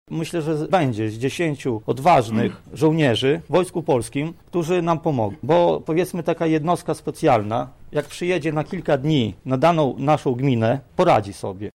hodowca trzody chlewnej: